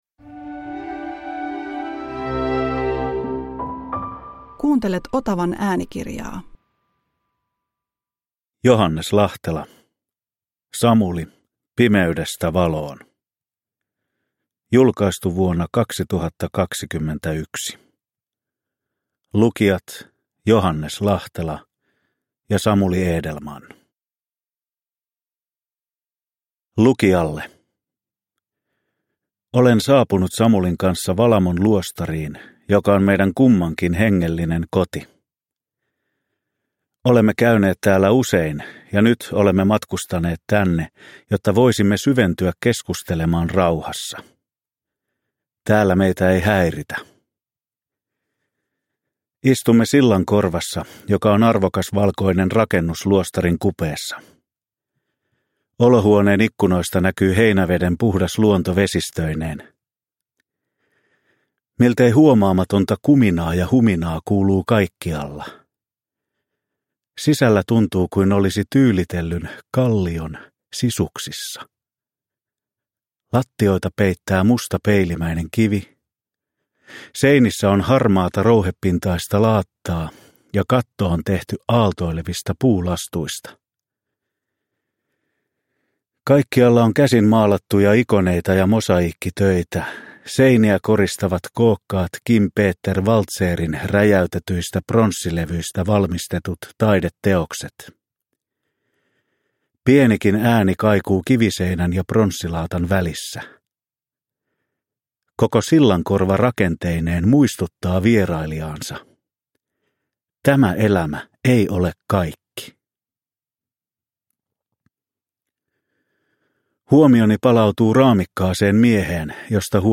Samuli – Ljudbok – Laddas ner